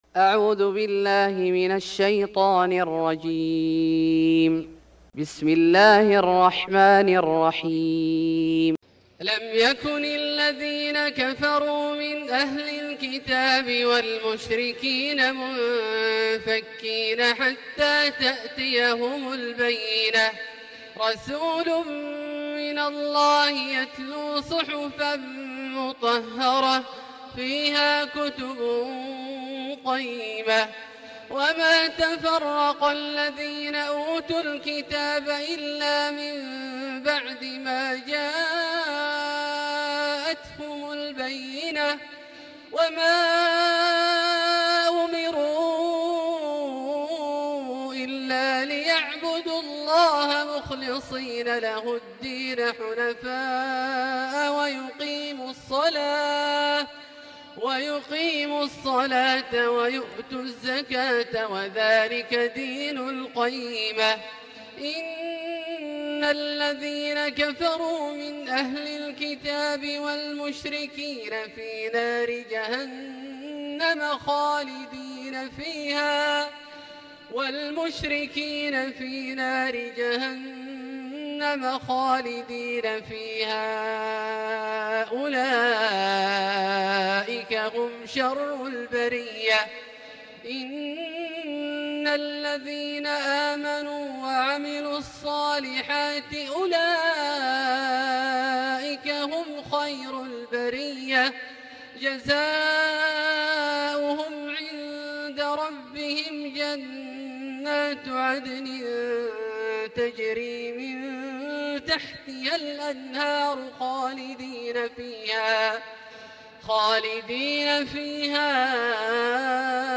سورة البينة Surat Al-Bayyinah > مصحف الشيخ عبدالله الجهني من الحرم المكي > المصحف - تلاوات الحرمين